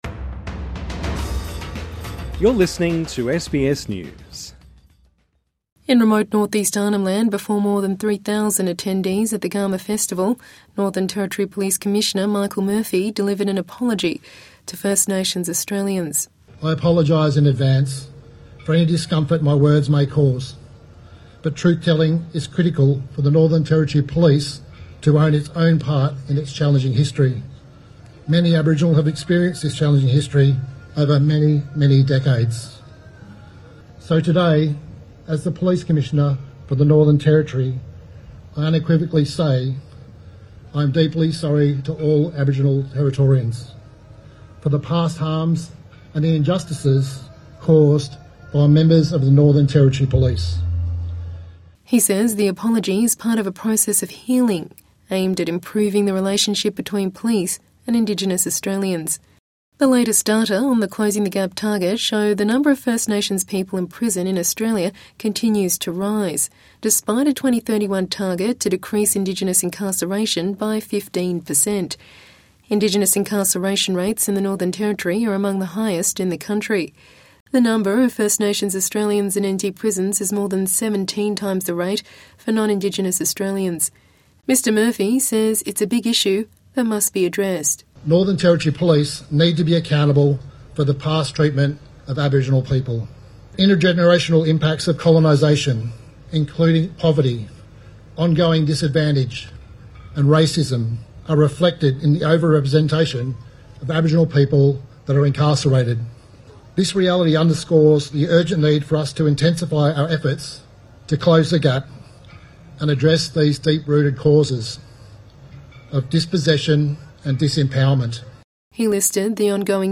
Aboriginal groups have welcomed an apology delivered by the Northern Territory Police chief for injustice and harm inflicted on First Nations Australians in the past 150 years. Commissioner Michael Murphy delivered the apology at Australia's largest Indigenous gathering, the Garma Festival, which also heard from the Prime Minister on the direction of Indigenous policy after the Voice referendum result.